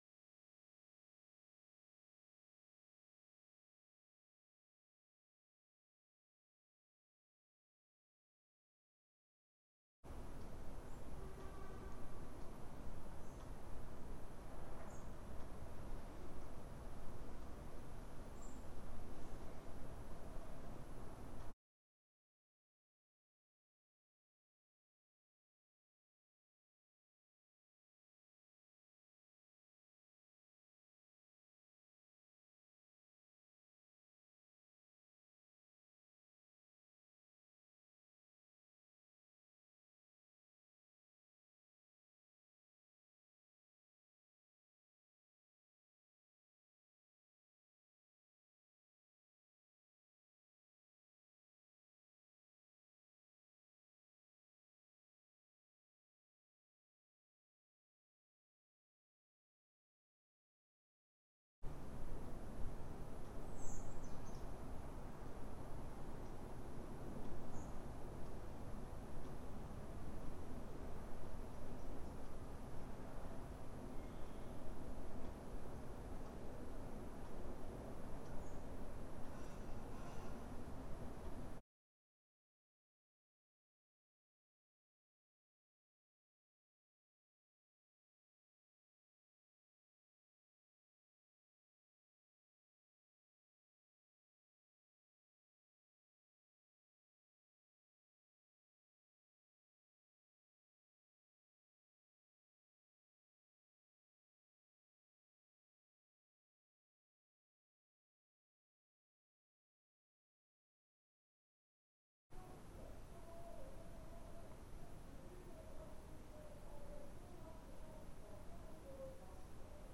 improvised and contemporary music